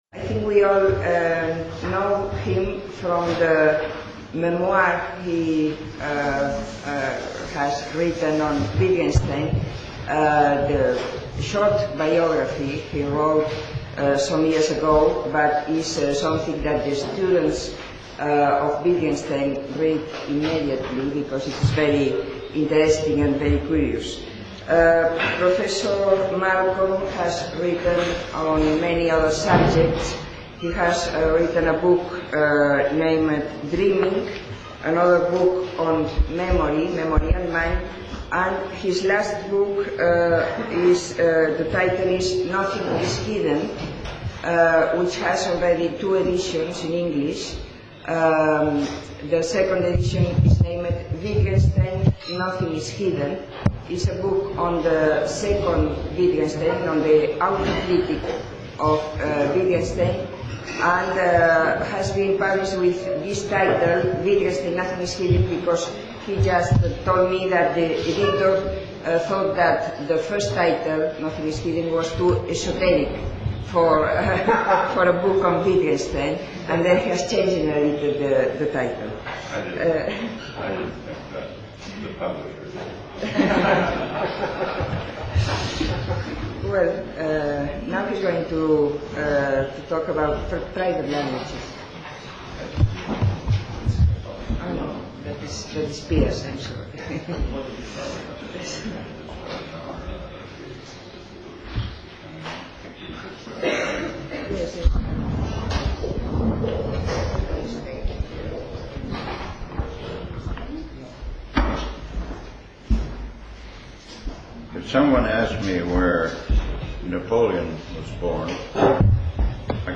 [Conferència]
[Conferència]      Malcolm, Norman  1989-04  Text Complet  846.mp3 51.85 Mb | MP3     Compartir  </>  Ponència de Norman Malcolm en el marc del Simposi celebrat en motiu del centenari del naixement de Wittgenstein  Tots els drets reservats Mostra el registre complet de l'element